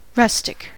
rustic: Wikimedia Commons US English Pronunciations
En-us-rustic.WAV